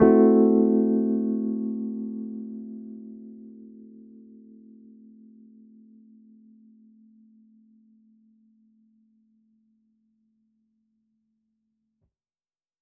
Index of /musicradar/jazz-keys-samples/Chord Hits/Electric Piano 3
JK_ElPiano3_Chord-Am6.wav